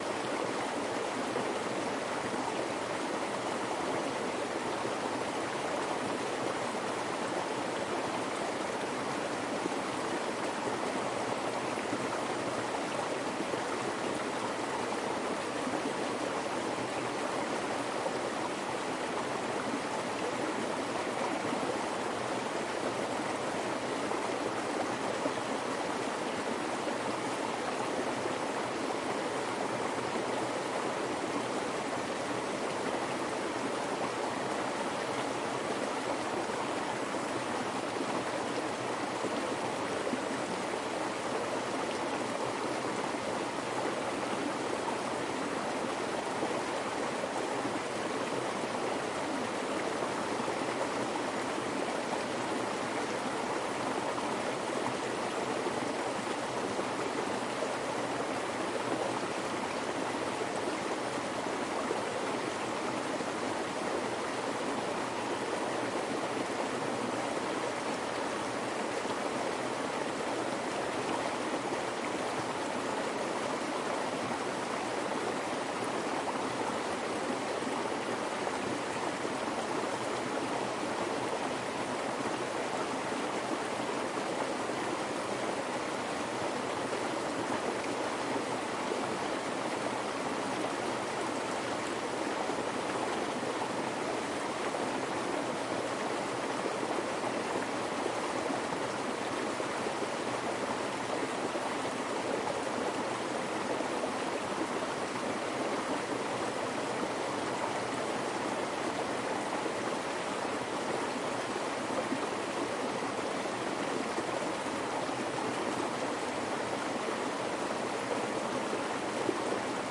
水 " 小溪 冬天的冰林 快速的水
描述：在森林里记录一条小溪。水快速移动。用H2N变焦记录仪记录。
Tag: 冬季 森林 小溪 现场录音